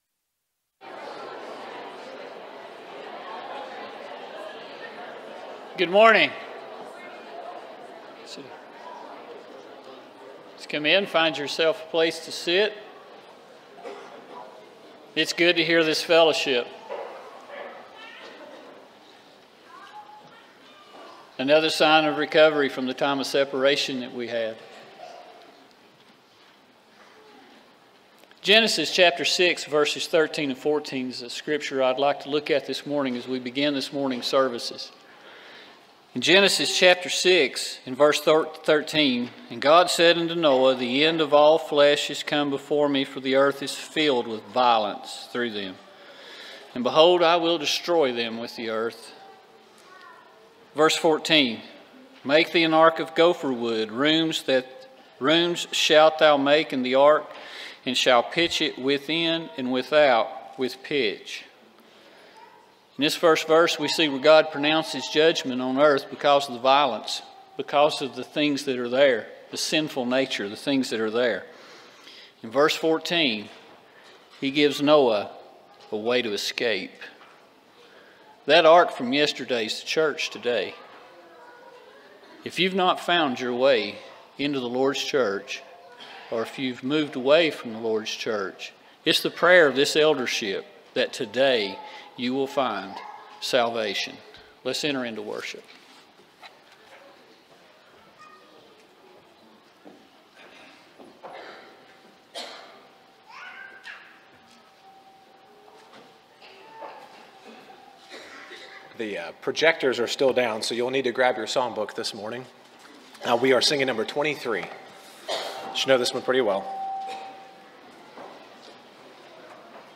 John 7:24, English Standard Version Series: Sunday AM Service